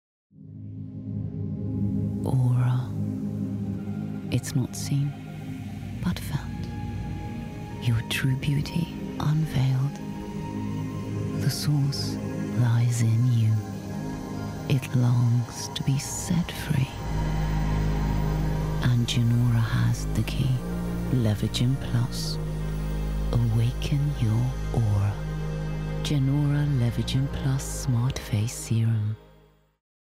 Best Female Voice Over Actors In December 2025
Adult (30-50)